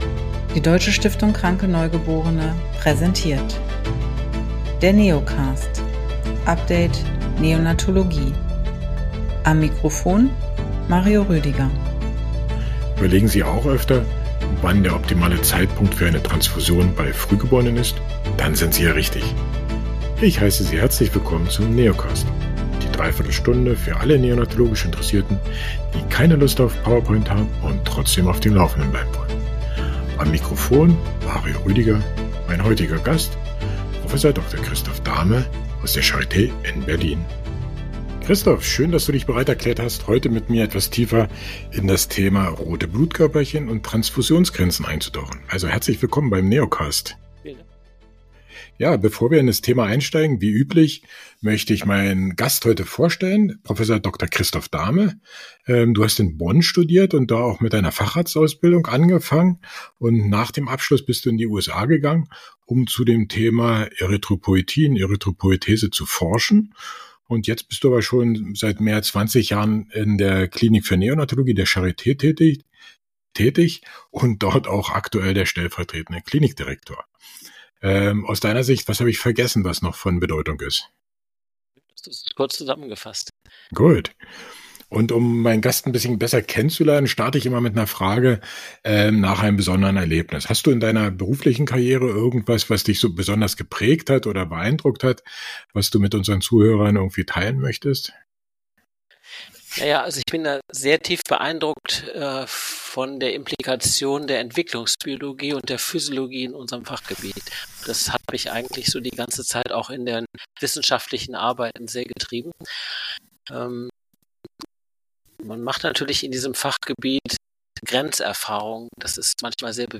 Wir bitten, die Tonqualität zu entschuldigen.